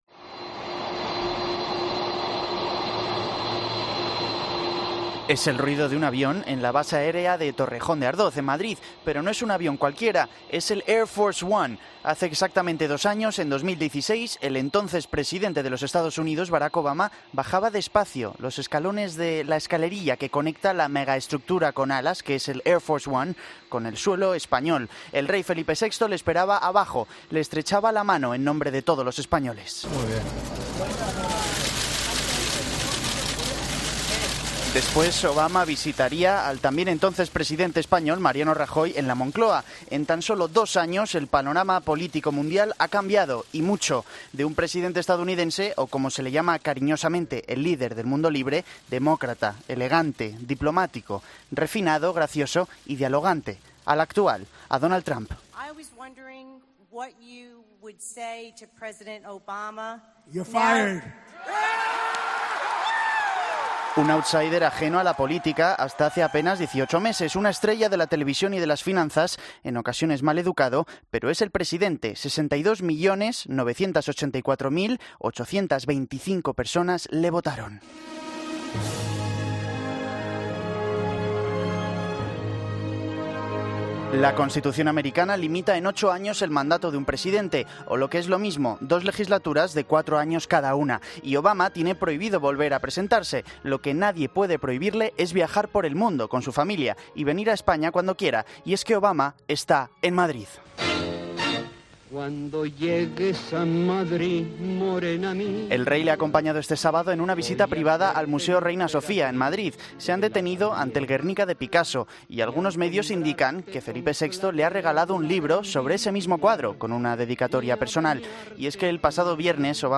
Es el ruido de un avión en la base de Torrejón.
Es sonido del Restaurante Filandón, en El Pardo, donde Barack Michelle y las Niñas cenaron la misma noche del viernes después de coincidir con el Duque de Alba Penélope Cruz y Almodóvar en casa del ex embajador estadounidense James Costos.
Que apareció rodeado de preciosas voces negras cantando una versión de Killing Me Softly.